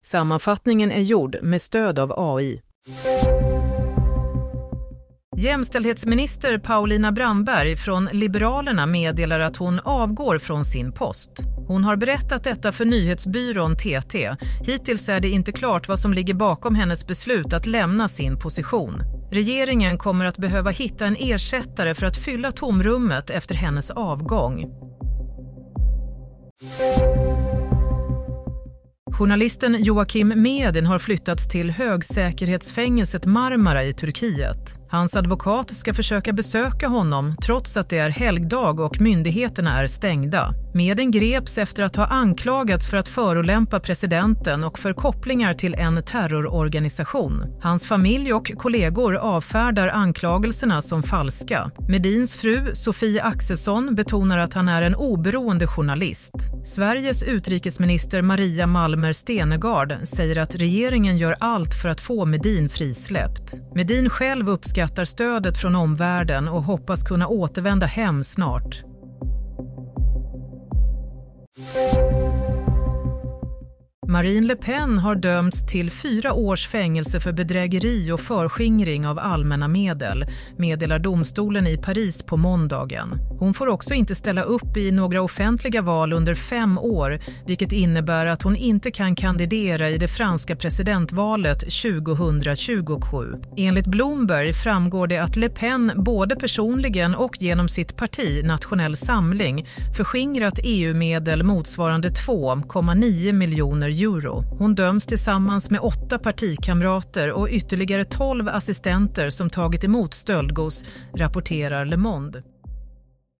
Play - Nyhetssammanfattning – 31 mars 16:00